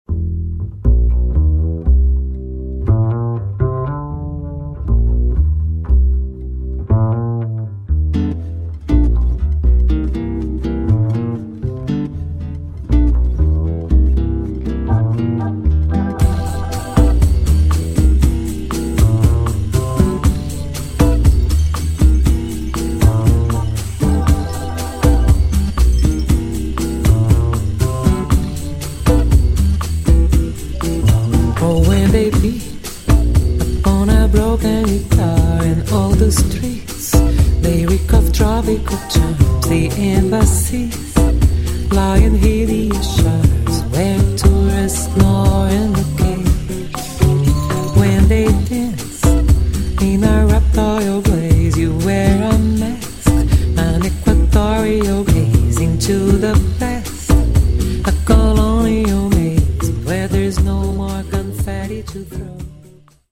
Bossa nova to klimat, w którym czuje się jak ryba w wodzie.